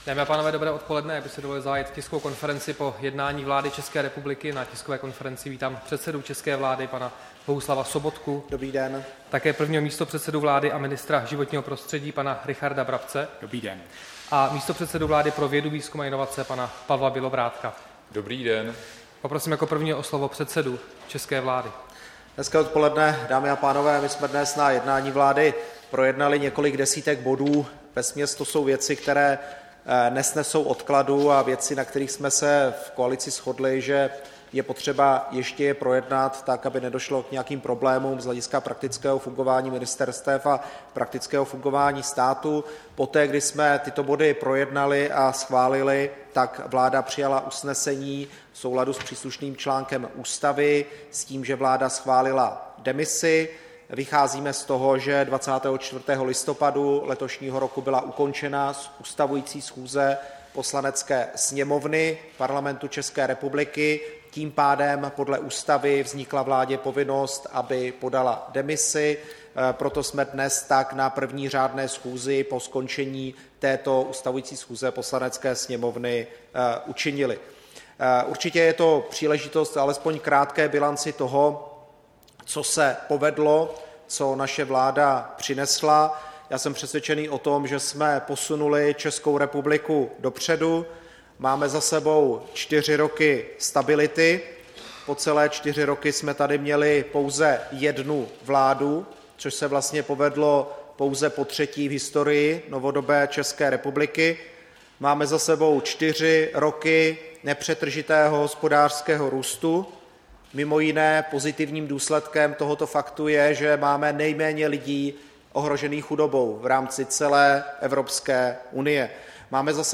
Tisková konference po jednání vlády, 29. listopadu 2017